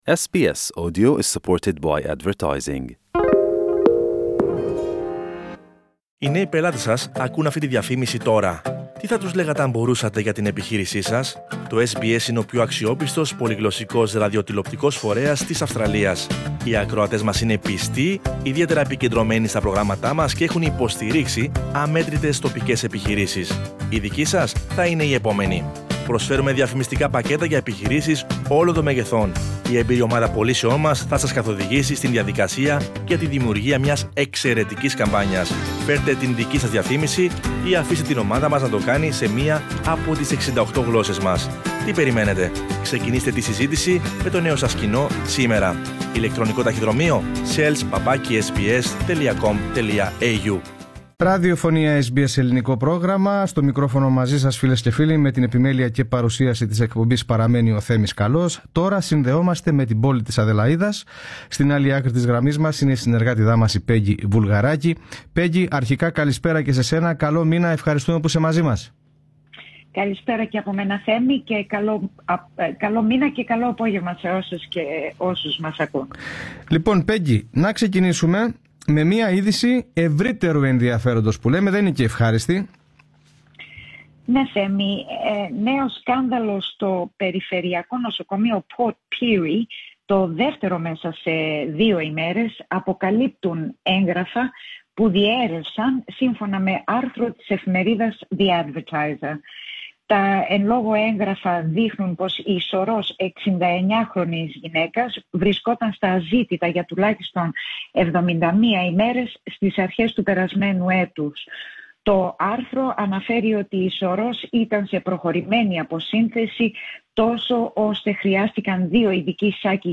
Ακούστε την ανταπόκριση από την Αδελαΐδα